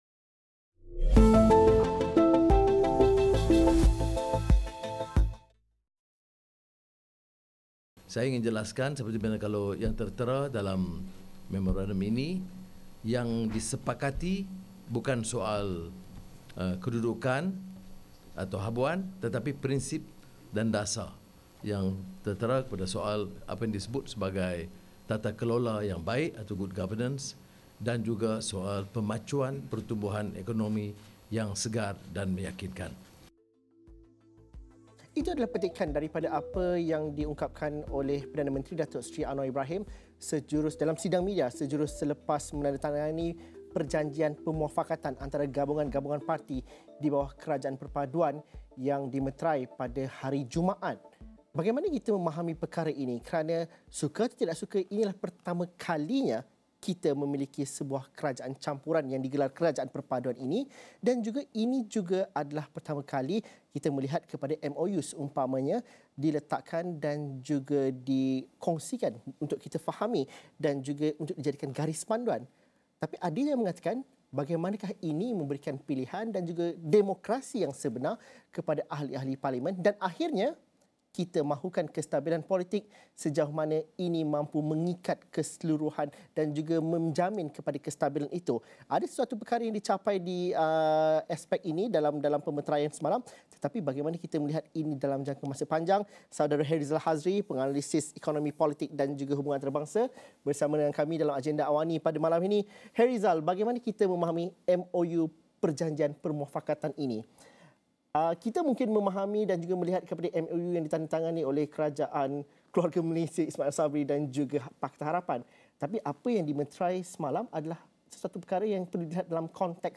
Diskusi 8.30 malam.